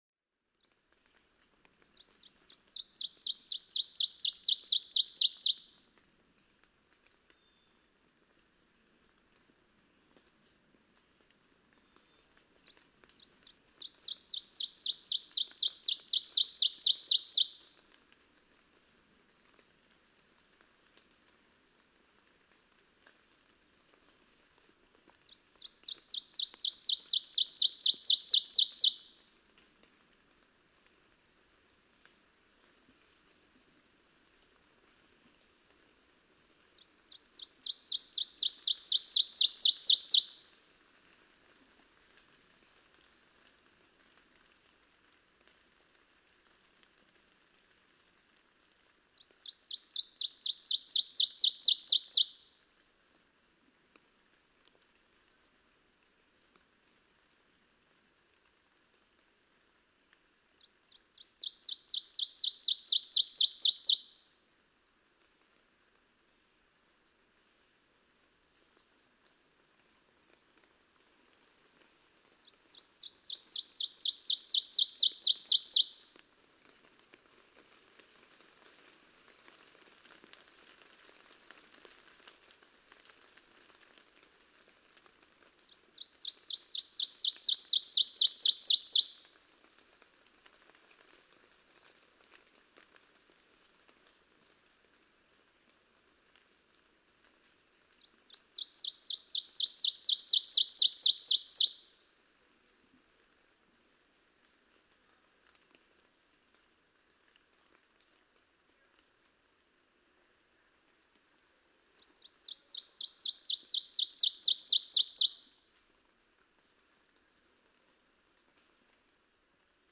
Ovenbird
The typical daytime teacher song of a male ovenbird, in rain. The tranquility of standing peacefully in nature, recording a singing bird, is broken at 2:15.
Daughters of the American Revolution State Forest, Goshen, Massachusetts.
534_Ovenbird.mp3